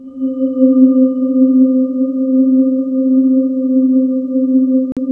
Index of /90_sSampleCDs/USB Soundscan vol.28 - Choir Acoustic & Synth [AKAI] 1CD/Partition D/05-SPECTRE